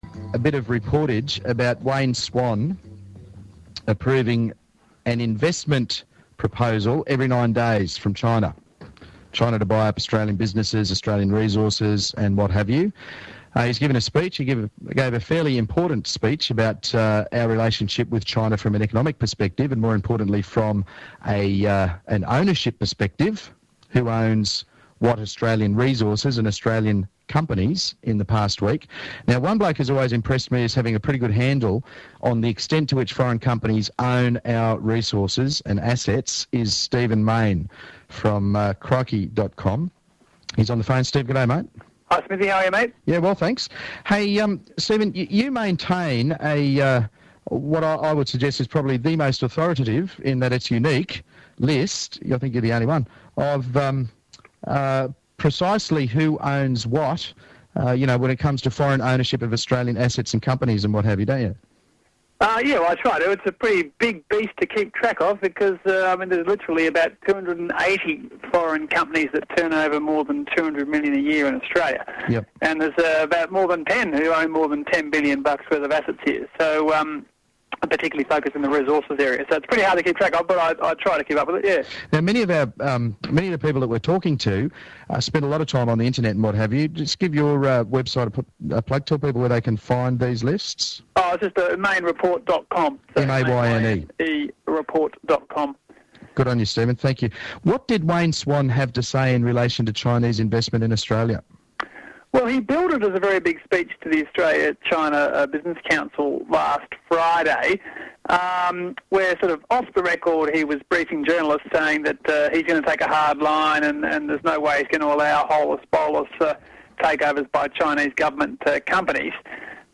Interviews on non-ABC radio stations